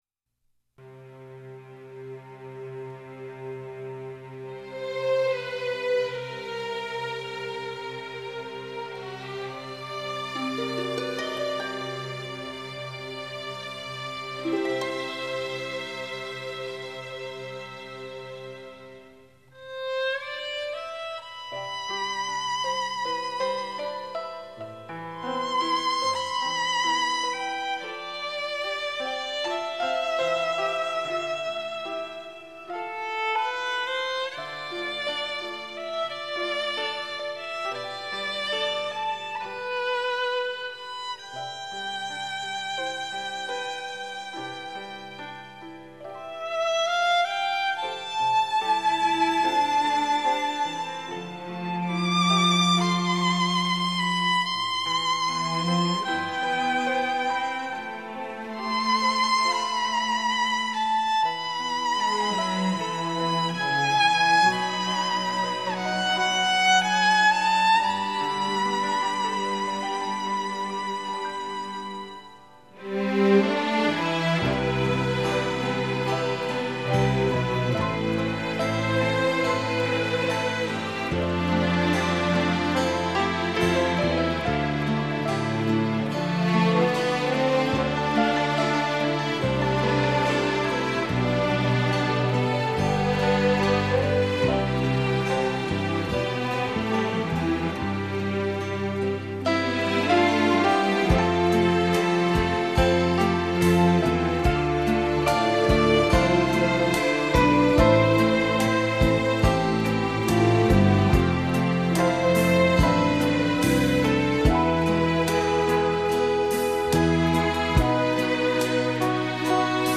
2005-02-26 07:28 平板 | 收藏 | 小 中 大 优美小提琴－时光倒流七十年 此曲出自拉赫玛尼诺夫根据意大利著名小提琴家帕格尼尼(N． Paganini，1782-1840)的第二十四首小提琴独奏《随想曲》(a小调)的主题为基础，用变奏曲的形式写成的《帕格尼尼主题狂想曲》中的第18变奏：如歌的行板，这感人的旋律相信会永远留在人们的记忆中的。